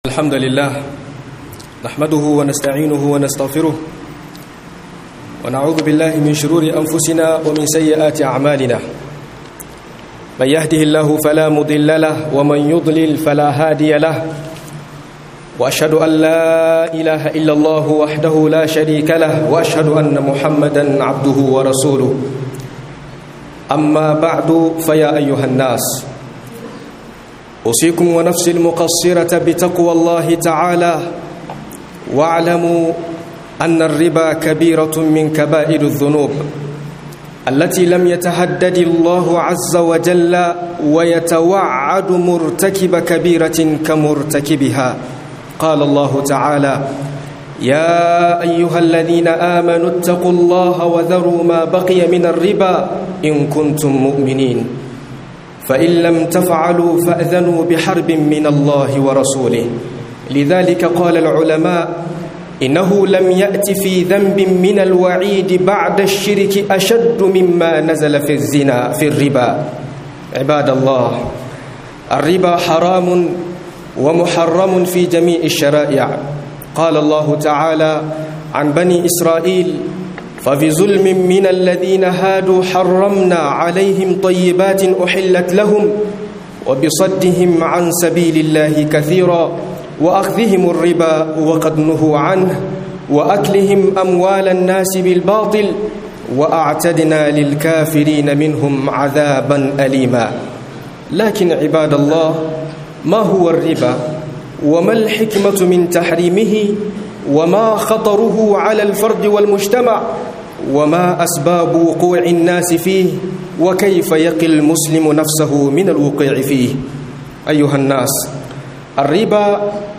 HADARIN RIBA A CIKIN AL'UMMA - MUHADARA